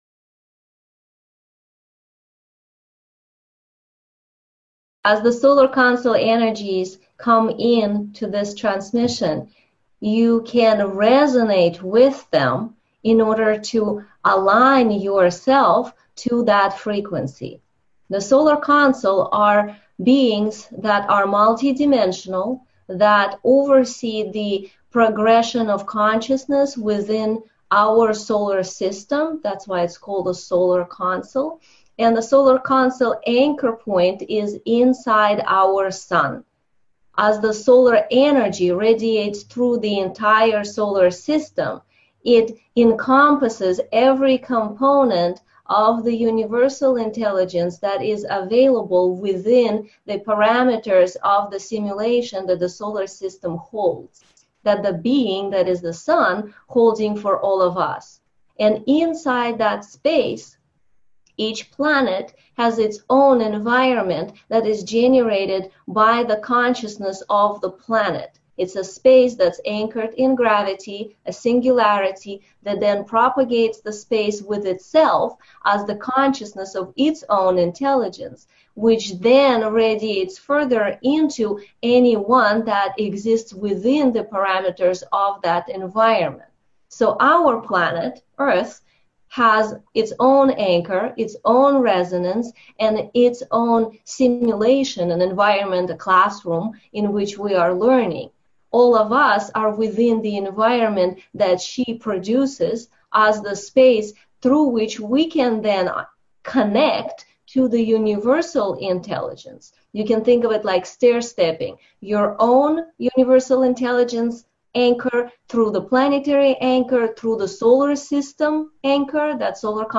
Live plugged into the Universal Intelligence Stargates 1-hour masterclass advanced LEVEL